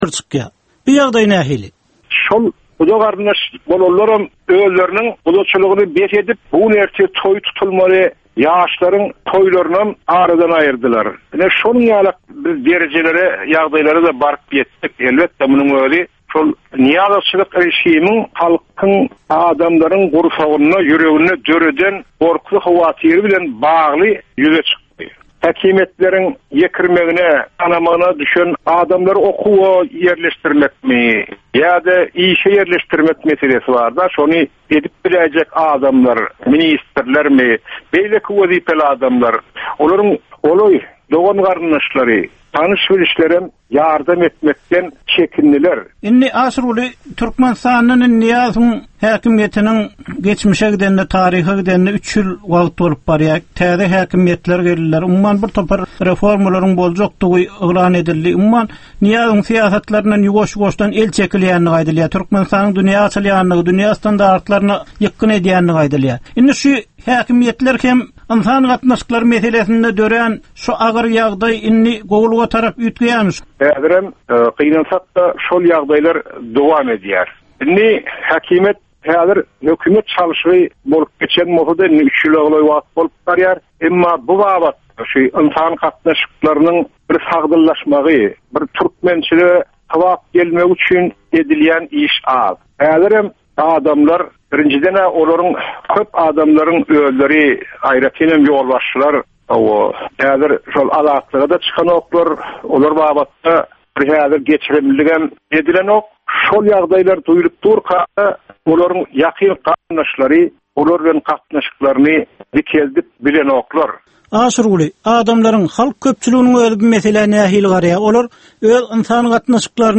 Türkmen jemgyýetindäki döwrün meseleleri. Döwrün anyk bir meselesi barada 10 minutlyk ýörite syn-geplesik. Bu geplesikde dinleýjiler, synçylar we bilermenler döwrün anyk bir meselesi barada pikir öwürýärler, öz garaýyslaryny we tekliplerini orta atýarlar.